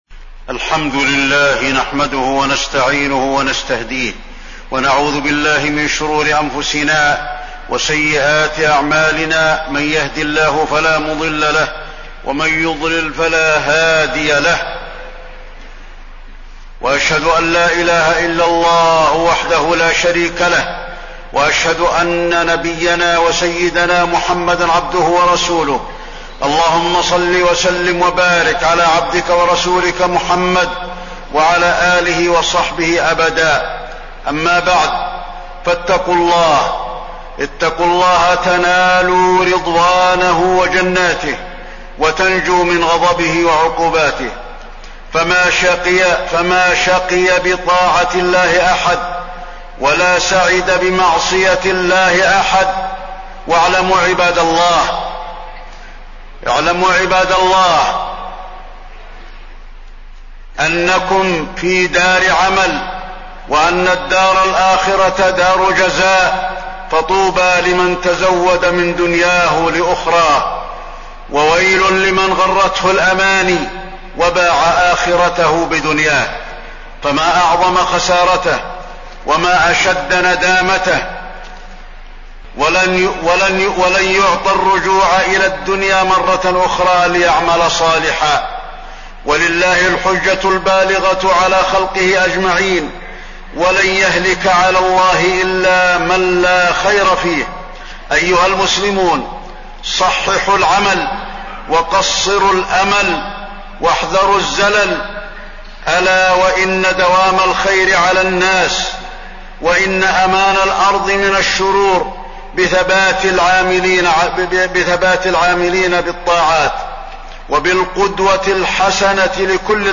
تاريخ النشر ٢٩ رجب ١٤٣٢ هـ المكان: المسجد النبوي الشيخ: فضيلة الشيخ د. علي بن عبدالرحمن الحذيفي فضيلة الشيخ د. علي بن عبدالرحمن الحذيفي محمد صلى الله عليه وسلم القدوة الحسنة